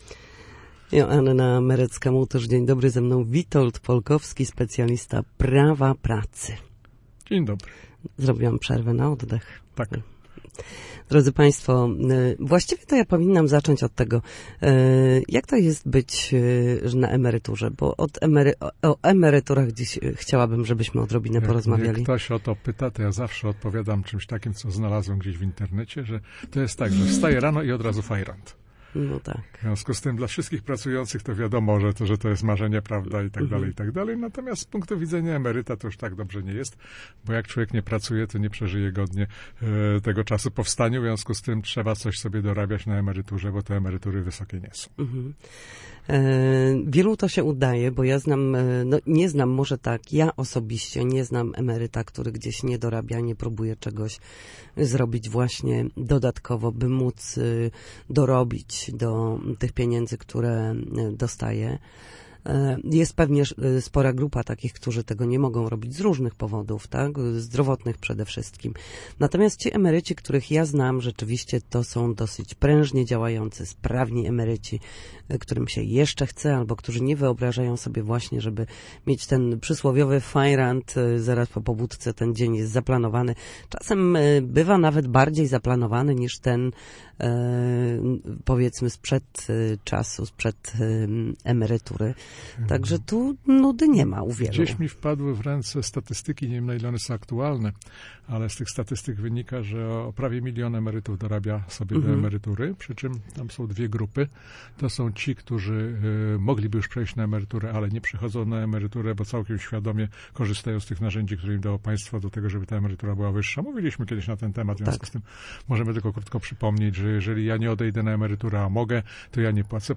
W każdy wtorek po godzinie 13 na antenie Studia Słupsk przybliżamy państwu zagadnienia dotyczące Prawa pracy.